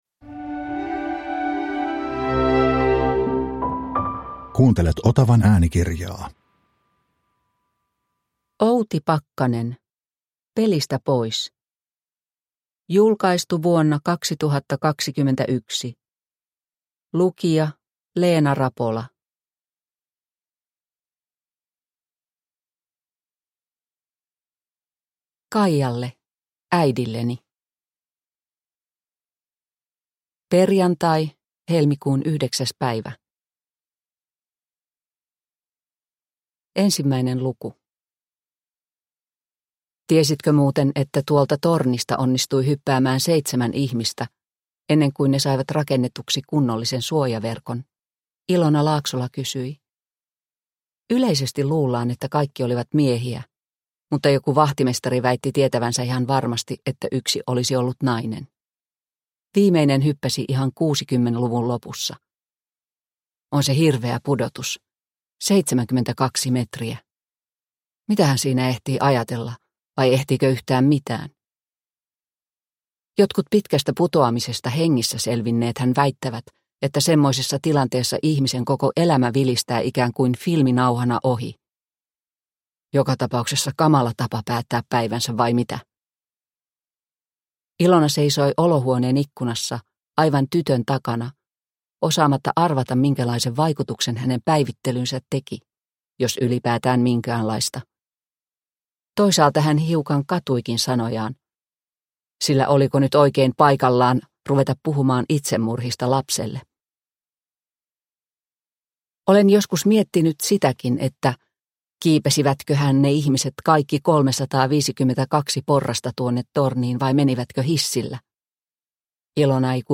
Pelistä pois – Ljudbok – Laddas ner